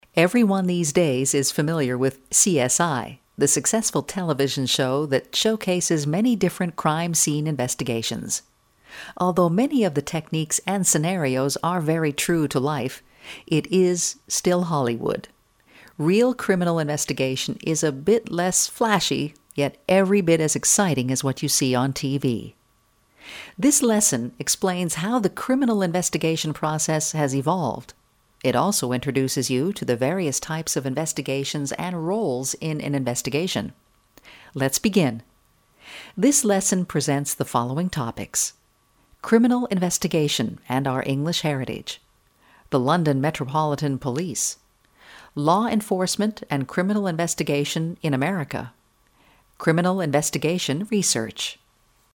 Smooth, rich female voice.
englisch (us)
Sprechprobe: eLearning (Muttersprache):